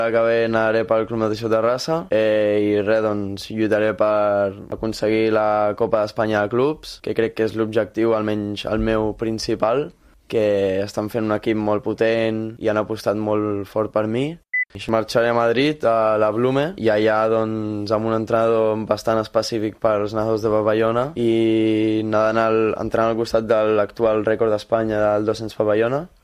Ho ha explicat en una entrevista al programa La Banqueta de Ràdio Calella Televisió.